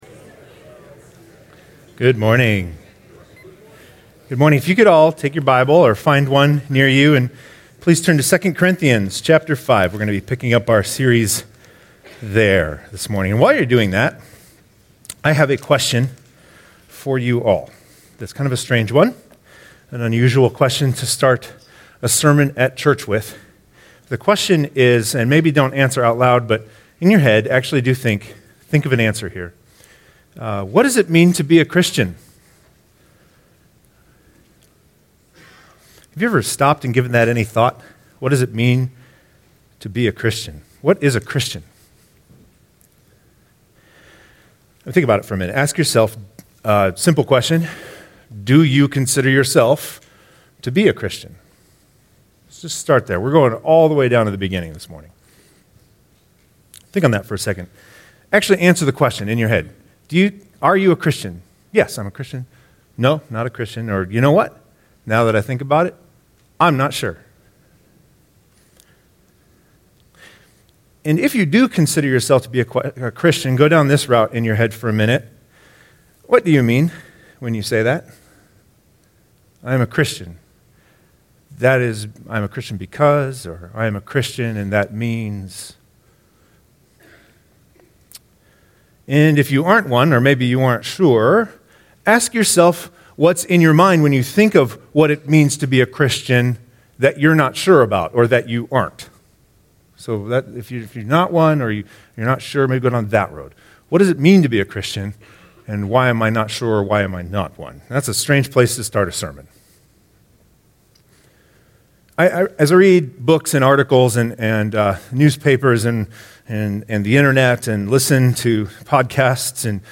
Ministers of a New Covenant Download sermon notes and discussion questions